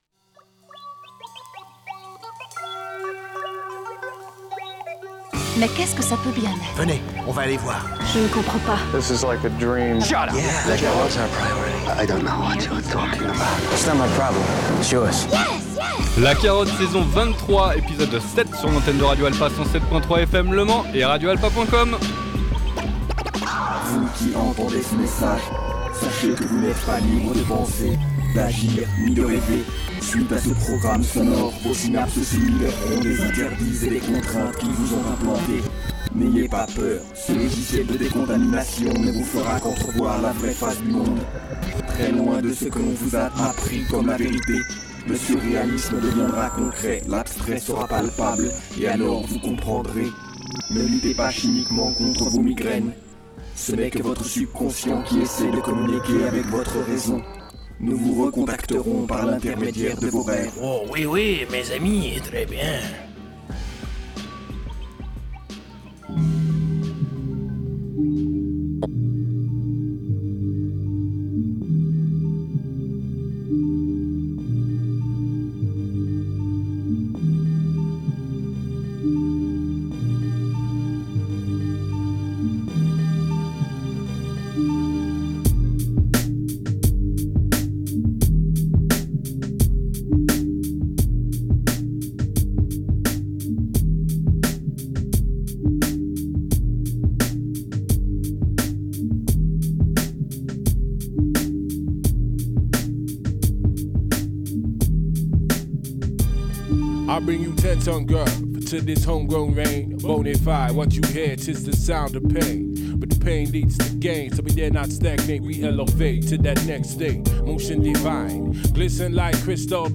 Carte Blanche #2 // Pour la deuxième carte blanche de cette saison 23, c'est un kiffeur de sons et collectionneur funky qui s'est installé dans le sofa de l'émission.